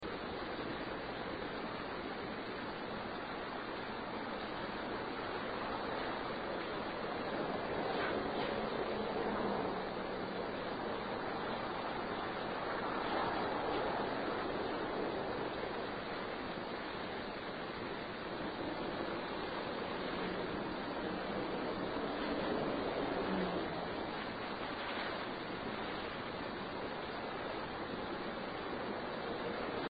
These are in the upstairs main room.
This seems to be a lament of some sort.